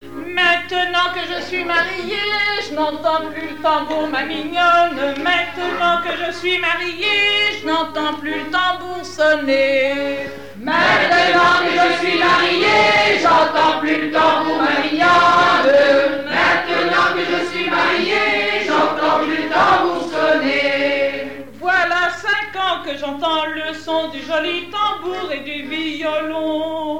Homme marié qui n'entend plus le tambour sonner Fonction d'après l'analyste gestuel : à marcher
Genre énumérative
Pièce musicale inédite